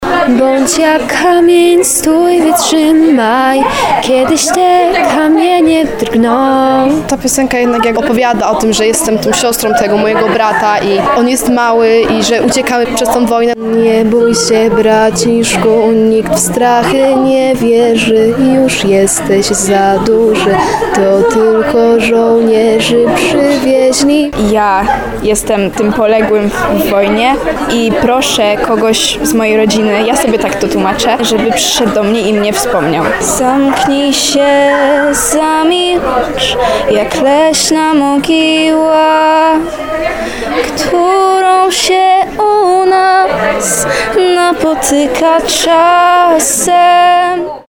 Dzieci i młodzież z małopolskich szkół prezentują dziś piosenki i pieśni patriotyczne na scenie tarnowskiego teatru.
25piosenka_patriotyczna.mp3